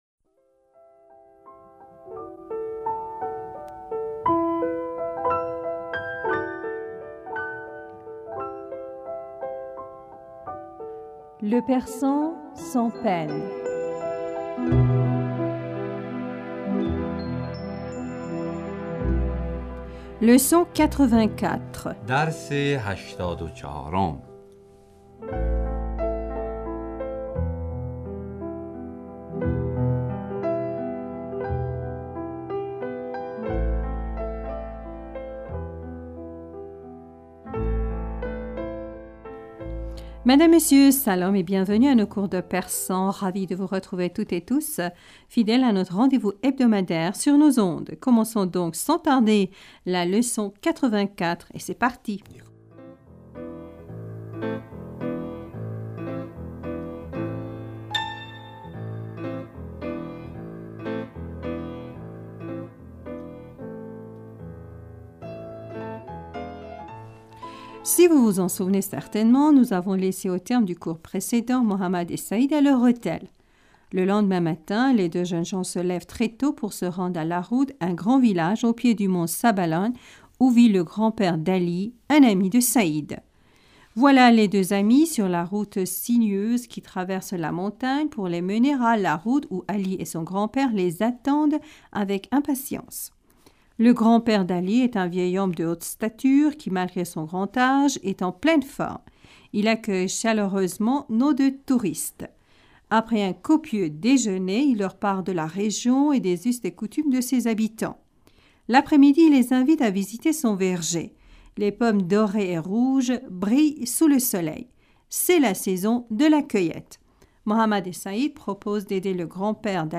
Nous vous proposons un autre cours de persan, la 84ème leçon.